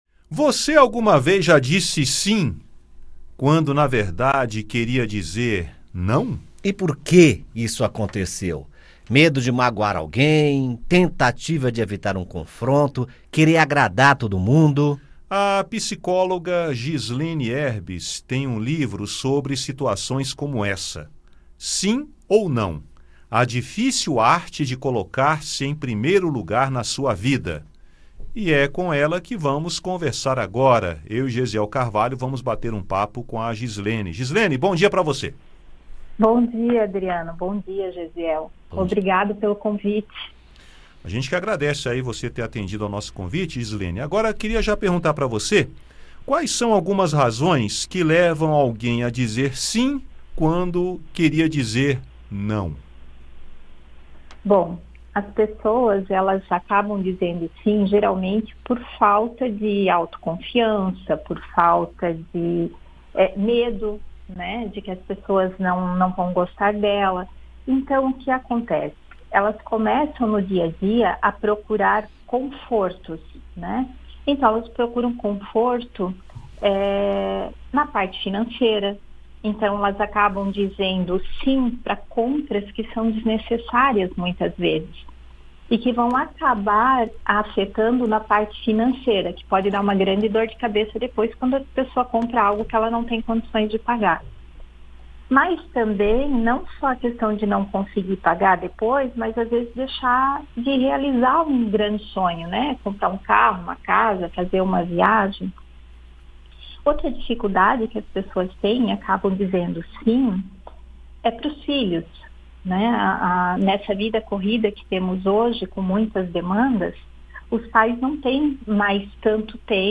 Psicóloga fala sobre dificuldade das pessoas em dizer "não"